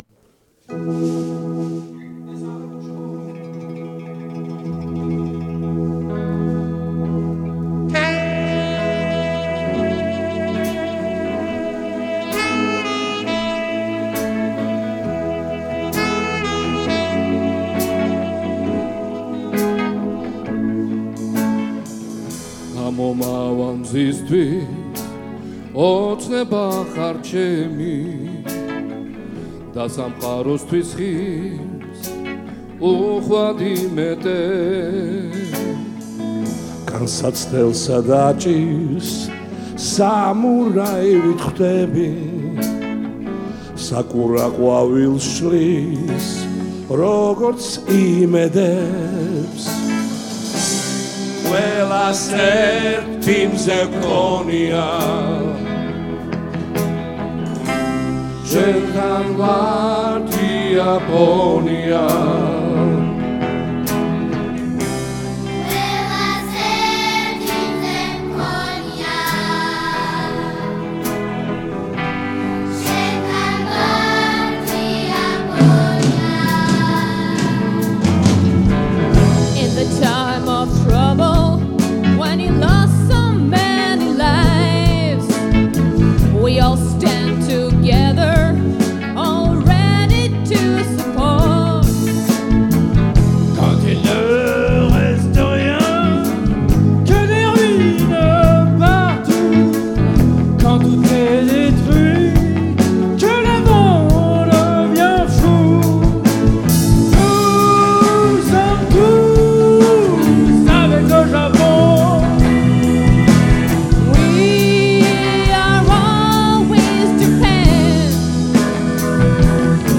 საფრანგეთის ელჩის, ბატონი ერიკ ფურნიეს ინიციატივით 2011 წლის 1 ივლისს გაიმართა ღონისძიება, რომელზეც შესრულდა იაპონიის სოლიდარობის სიმღერა. ბატონმა ერიკ ფურნიემ დაუკრა საქსაფონზე
სხვა მუსიკოსებმა დაუკრეს გიტარაზე, დოლზე და სხვა მუსიკალურ ინსტრუმენტებზე. N°1 მუსიკალური სკოლის ბავშვთა გუნდმა, იაპონიის ელჩმა საქართველოში, ბატონმა მასაიოში კამოჰარამ და სხვა დიპლომატებმა შეასრულეს სიმღერა ინგლისურ, იაპონურ, ფრანგულ, უკრაინულ და ქართულ ენებზე. მონაწილეებს ეცვათ მაისური, რომელზეც იაპონიის დროშა იყო გამოსახული. ეს მაისური საგულდაგულოდ დამზადდა ამ ღონისძიებისთვის.
solidarity_song.mp3